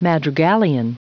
Prononciation du mot madrigalian en anglais (fichier audio)
Prononciation du mot : madrigalian
madrigalian.wav